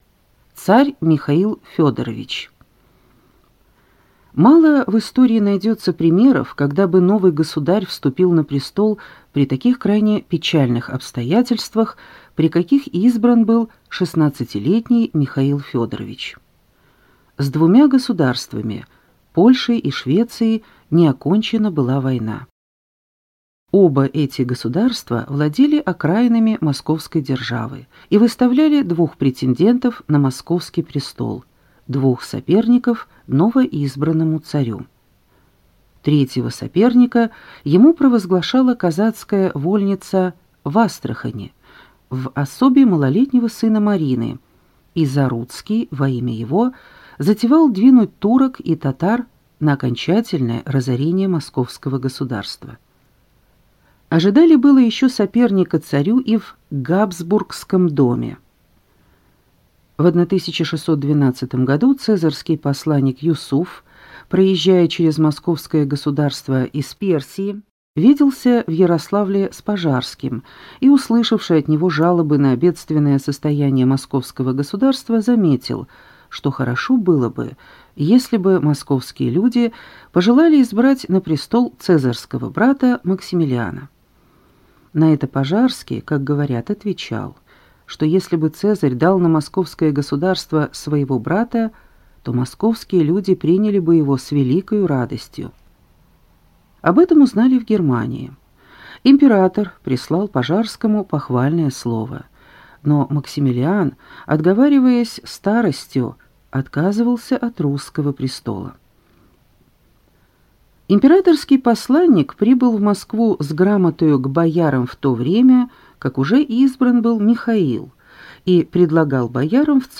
Аудиокнига Русская история. Том 7. Господство дома Романовых до вступления на престол Екатерины II | Библиотека аудиокниг